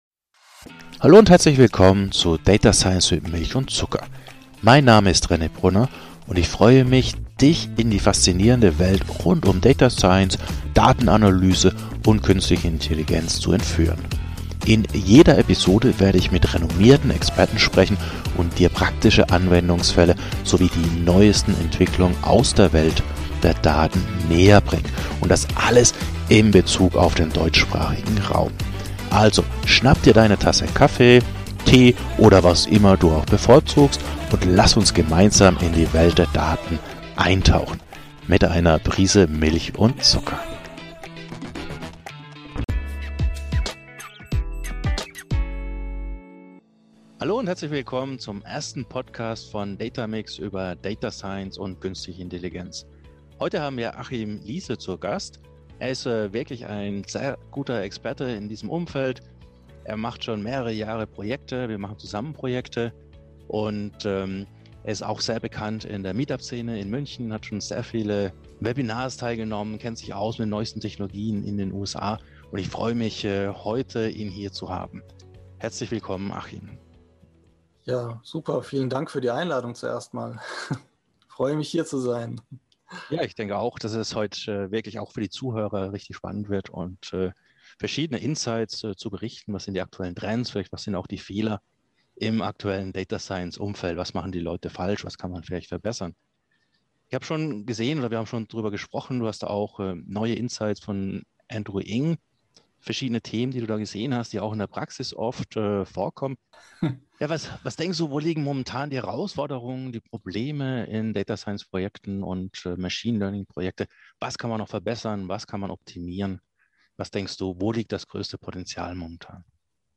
Wir beleuchten typische Herausforderungen in deutschen Unternehmen, den Wandel vom Data Scientist zum ML Engineer und wie sich durch moderne Infrastruktur Use Cases schneller und skalierbarer umsetzen lassen. Ein Gespräch für alle, die KI nicht nur diskutieren, sondern wirklich produktiv machen wollen.